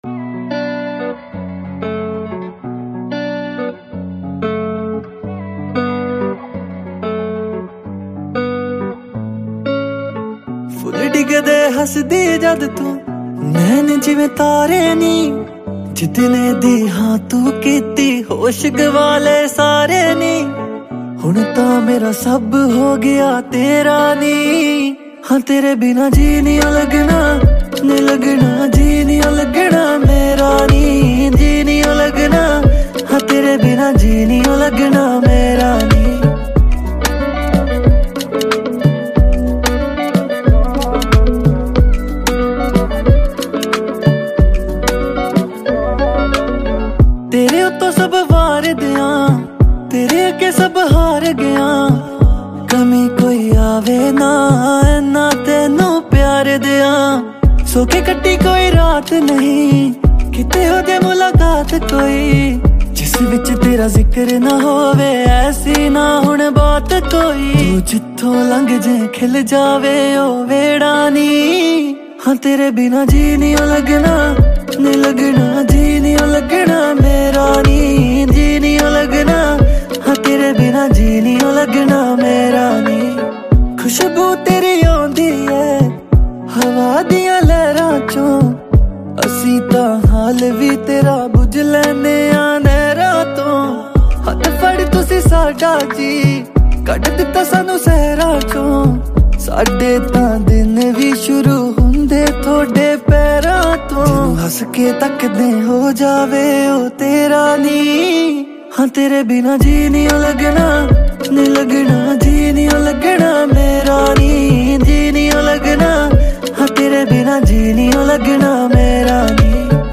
a romantic Punjabi song
Punjabi Songs